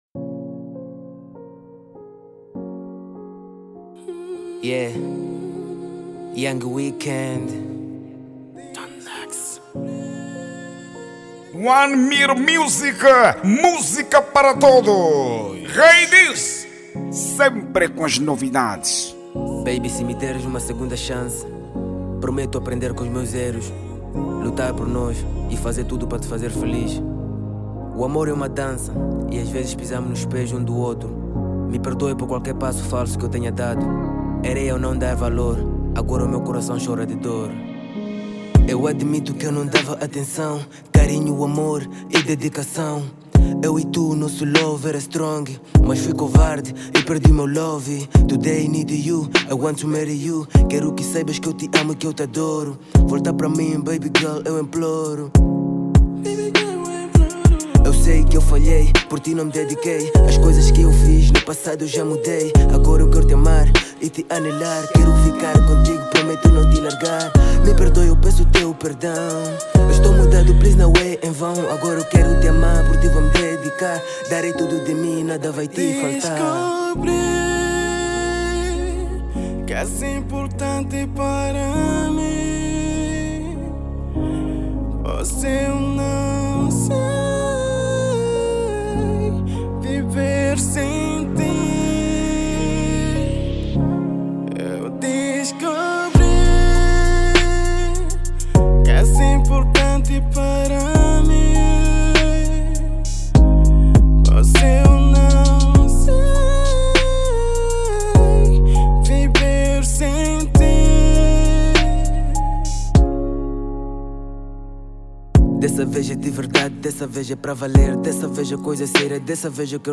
Gênero :R&B